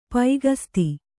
♪ paigasti